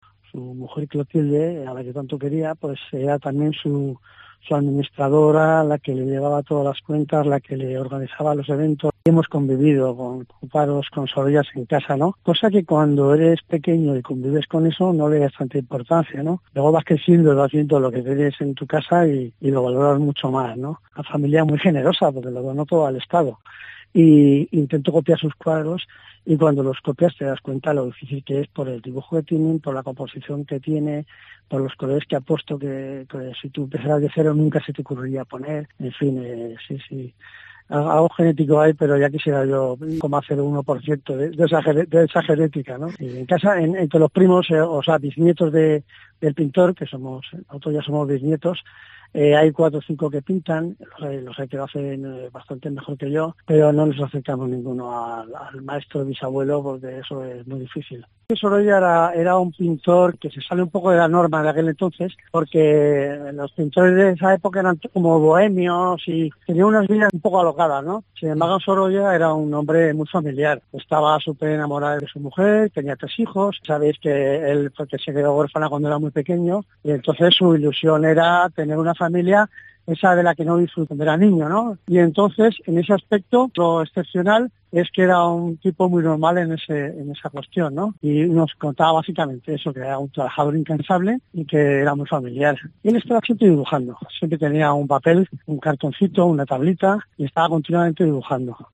Ha recordado en los micrófonos de COPE Valencia cómo ha sido convivir con el arte desde nacimiento: "hemos convivido con Sorollas en casa, de pequeño no le das importancia, le vas dando el valor con el paso del tiempo".